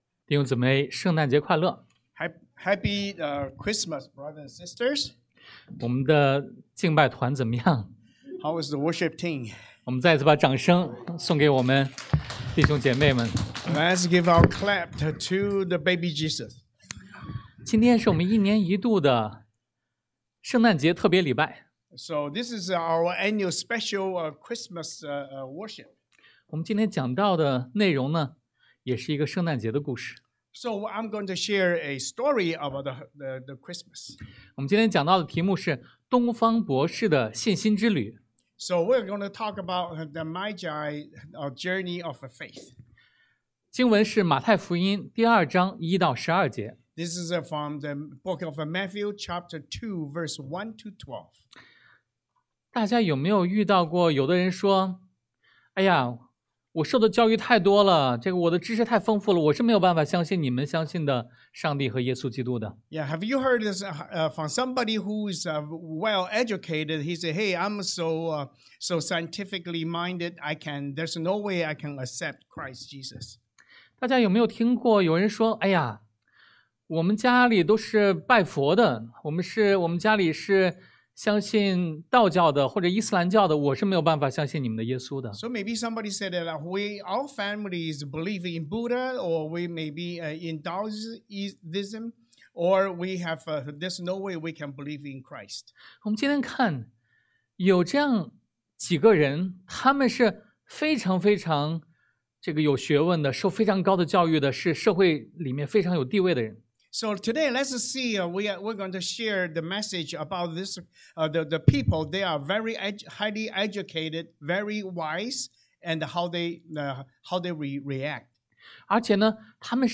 Passage: 馬太福音 Matthew 2:1-12 Service Type: Sunday AM